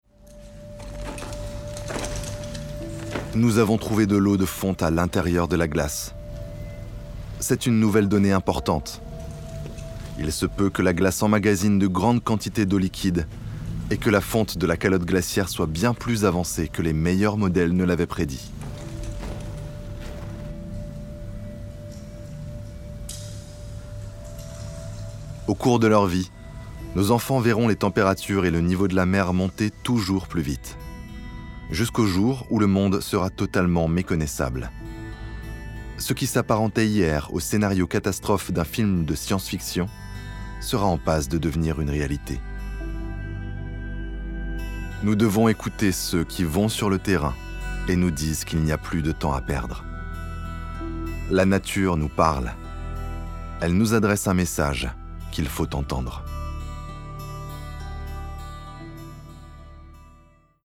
Narration Documentaire - Into The Ice
- Basse